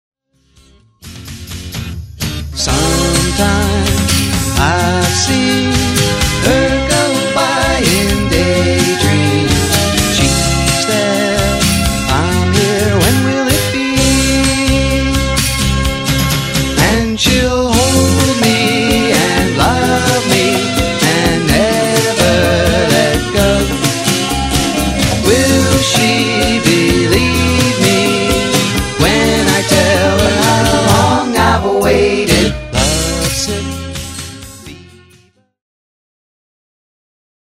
USING AN ANALOG 8-TRACK.
THESE TUNES HAVE A RETRO FLARE TO THEM.
IF YOU'RE A FAN OF 60'S AND 70'S POP ROCK,